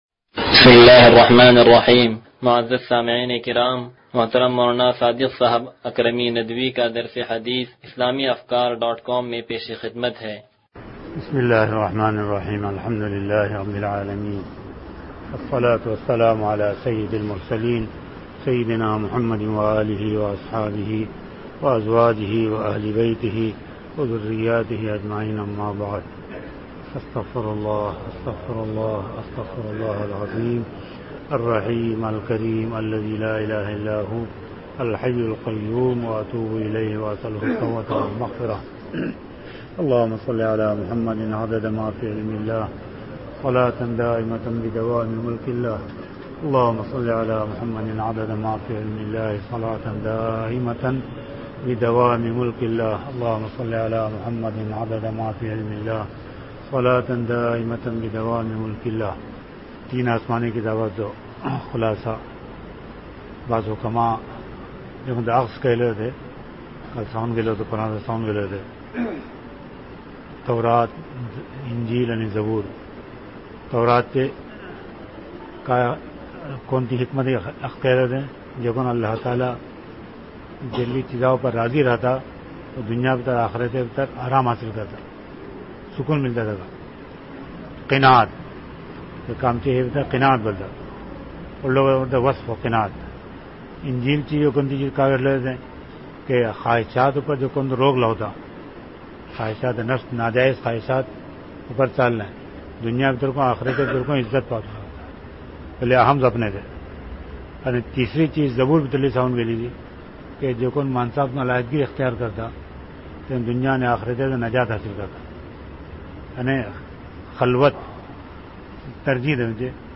درس حدیث نمبر 0080